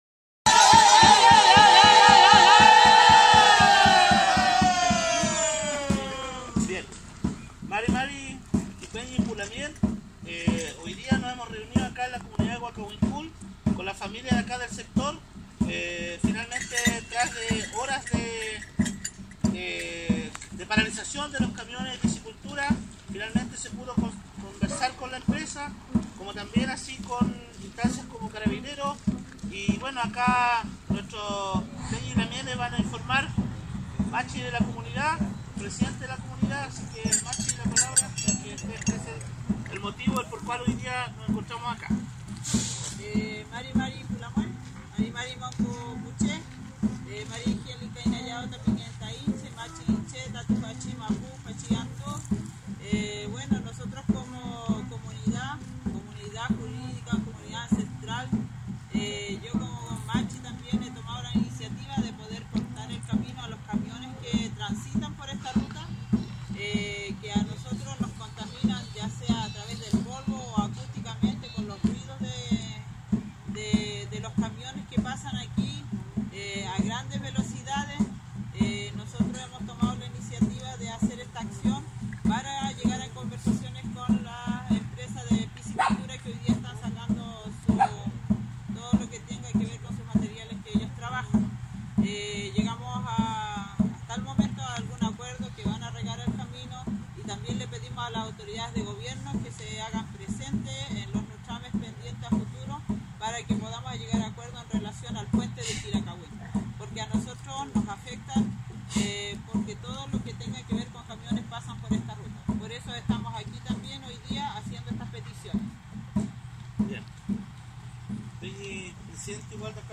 Para conocer los puntos acordados con la empresa y las determinaciones de la comunidad, dejamos un audio con diversas opiniones vertidas luego de las conversaciones con la empresa.